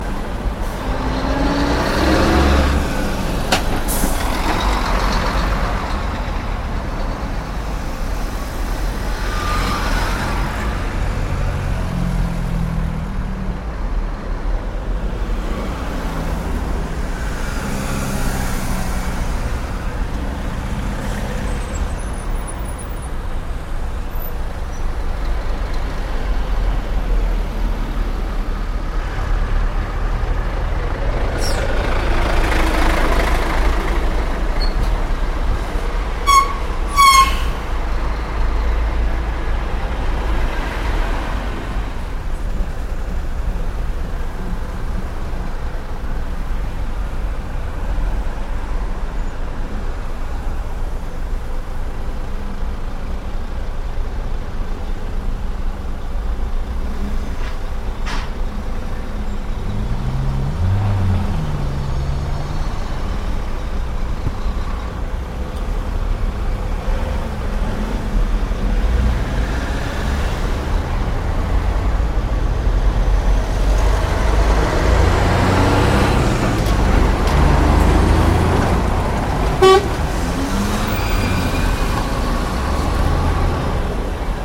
На этой странице собраны звуки автомобильных пробок — гудки машин, шум двигателей, переговоры водителей.
Машины медленно ползут в пробке